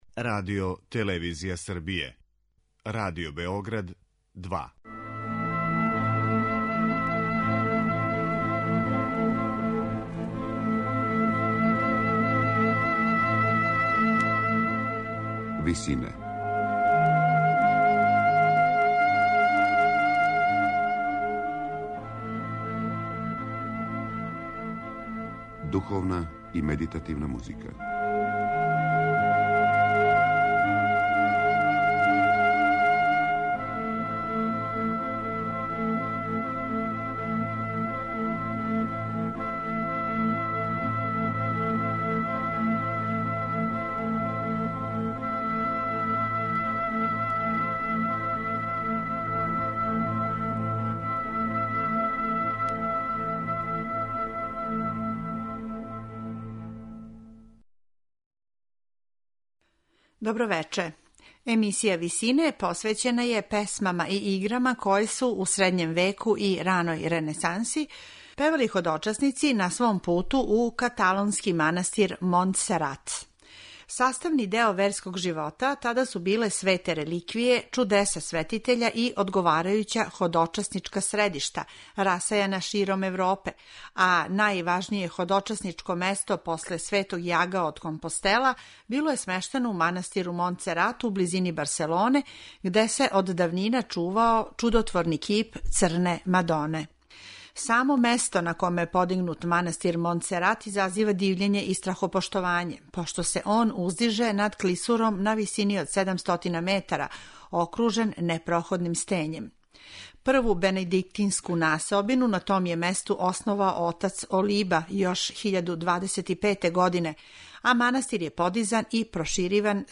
Песме и игре посвећене Црној Мадони
медитативне и духовне композиције
Слушаћете средњoвековне ходочасничке песме и игре посвећене Црној Мадони каталонског манастира Монтсерат, у близини Барселоне.